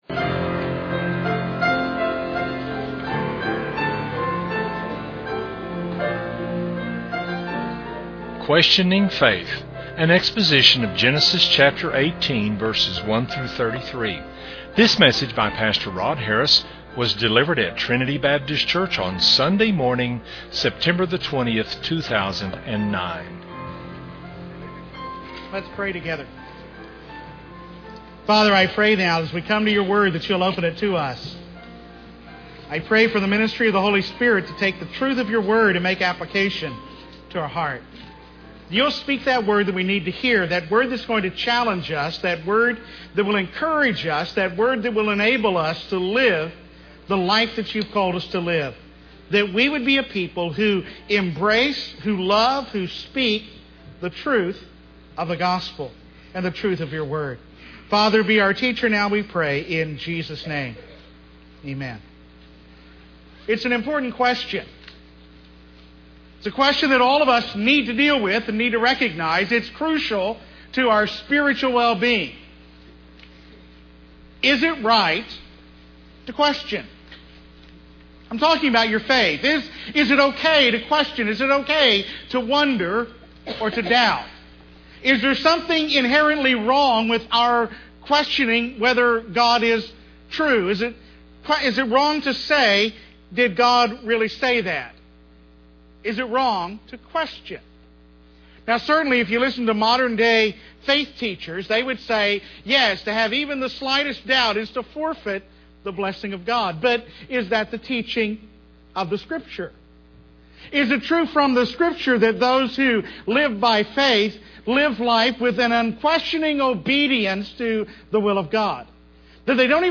was delivered at Trinity Baptist Church on Sunday morning, September 20, 2009.